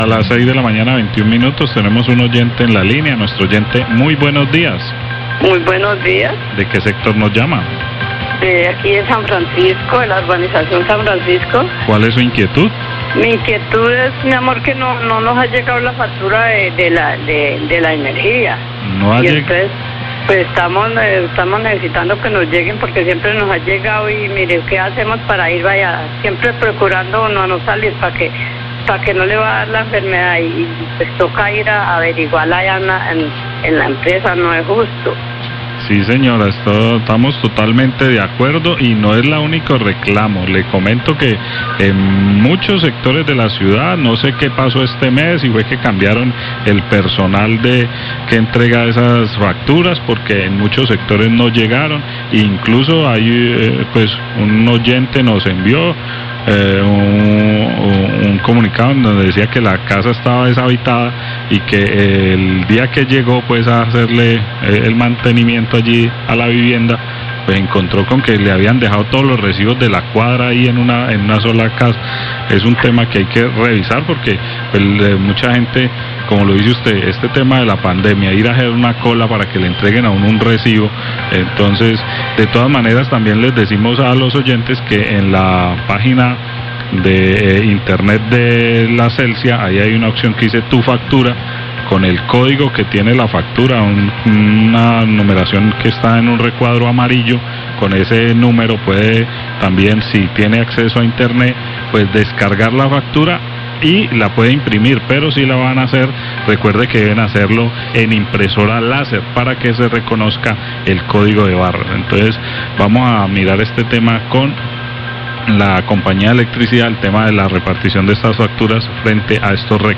Radio
Oyente llama a la cariñosa para reportar que aún no le ha llegado el recibo de energía a su casa.  Periodista manifestó que en varios sectores de Tuluá no ha llegado. También indicó que quienes tengan acceso a internet pueden descargar el recibo desde la web de Celsia.